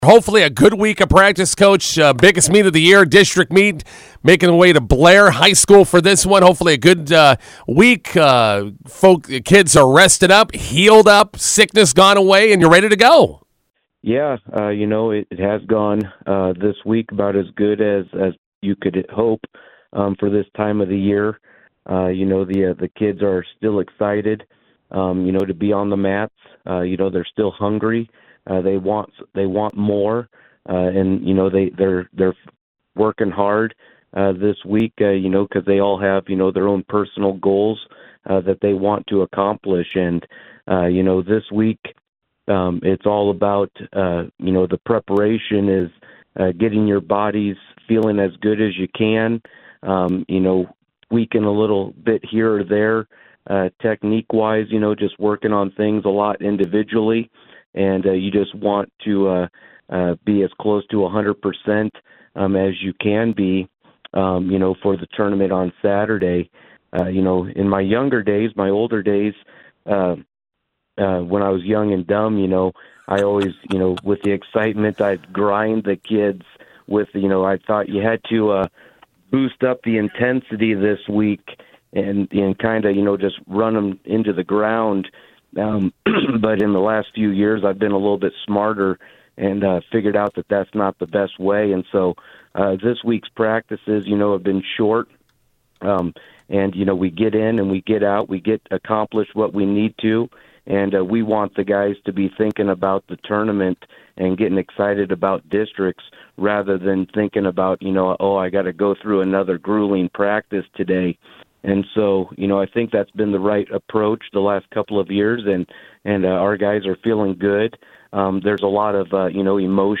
INTERVIEW: Bison wrestlers hit the road for Blair and Class B4 district meet.